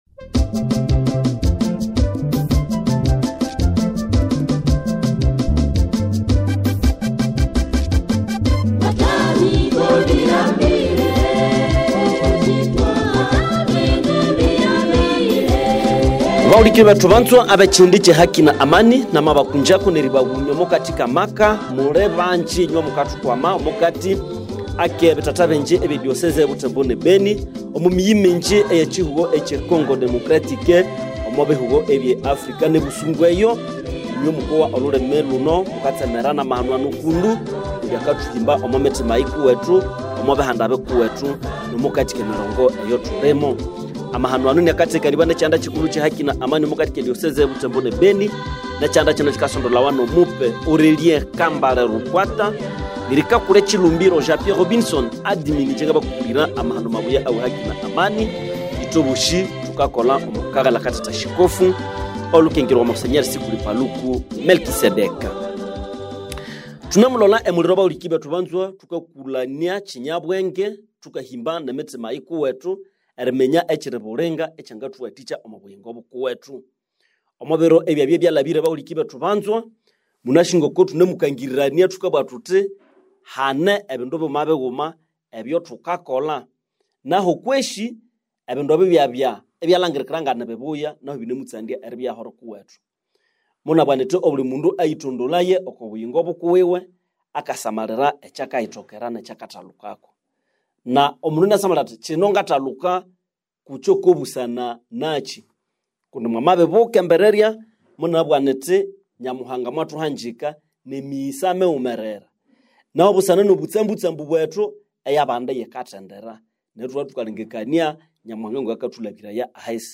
Émission Radio Écoutez l'émission ci-dessous